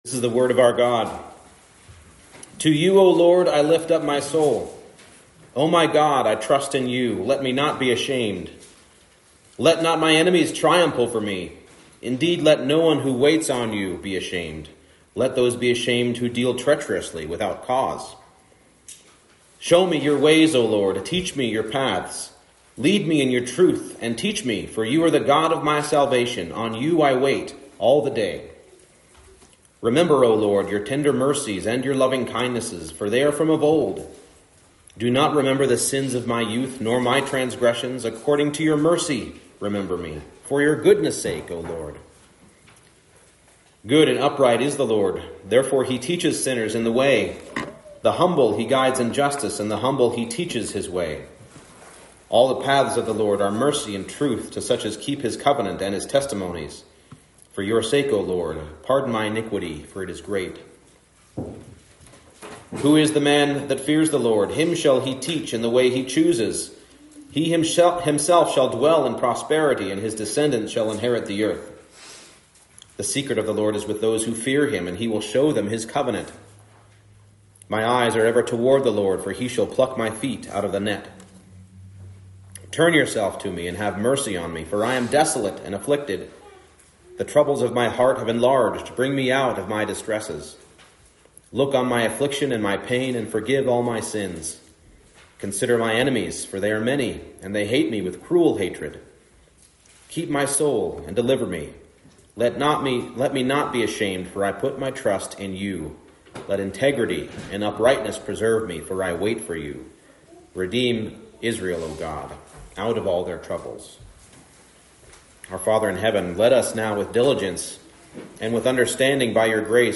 Psalm 25 Service Type: Morning Service The Lord guides His people who seek after Him in faith.